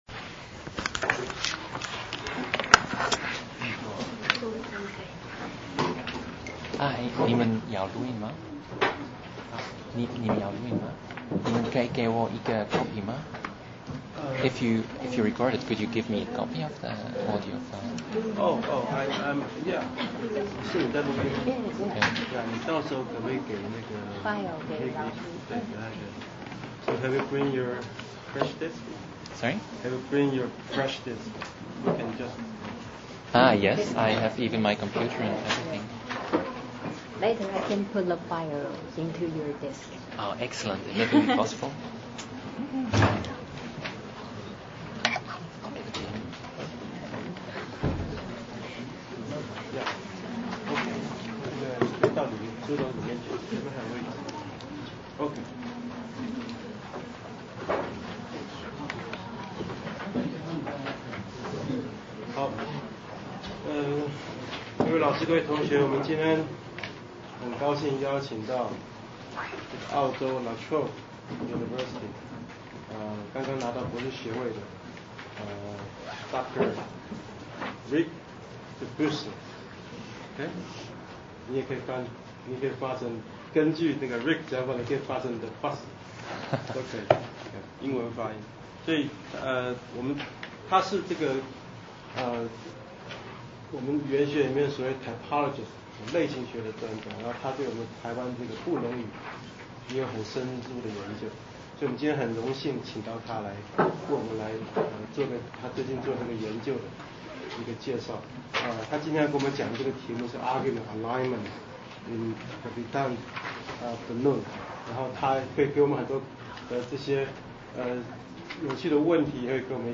Argument alignment in Takivatan Bunun: Many questions, some answers. Talk at National Tsing-Hua University, Taiwan, November 18, 2009.